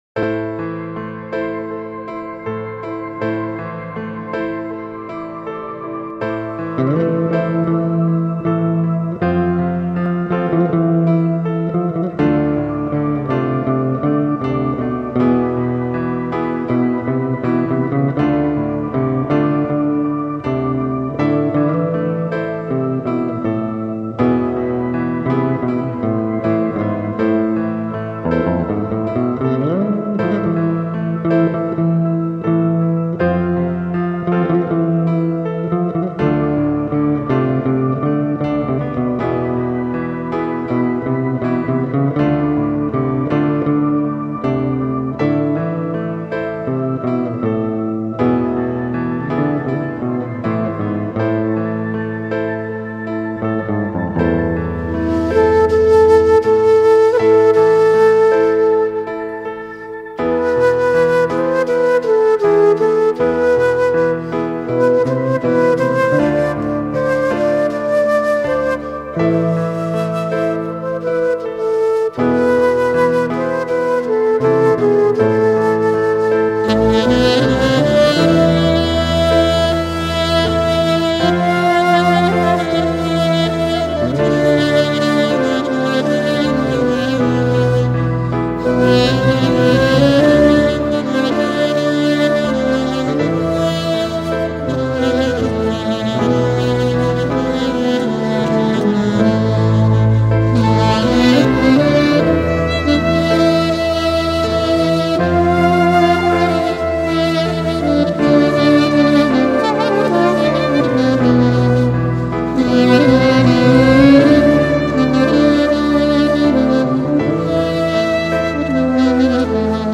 (Enstrümantal)